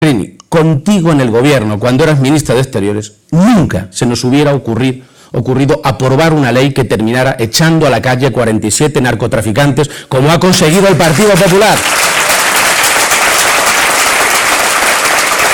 El secretario general del PSOE de Castilla-La Mancha, Emiliano García-Page, ha aprovechado que hoy ofrecía un mitin en la provincia de Cuenca para contestar algunas de las cosas que dijo Cospedal en el acto que celebró el pasado domingo en la capital conquense y señalar que votar al PSOE para que pierda el PP es hacerlo también contra los recortes y la mentira.